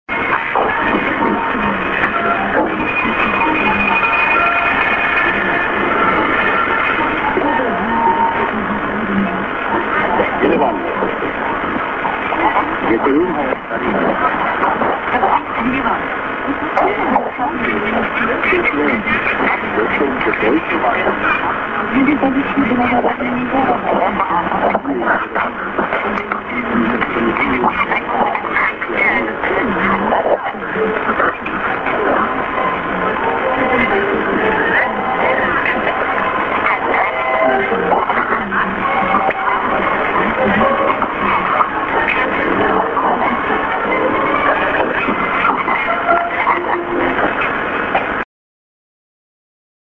St. IS->ID(man+women)->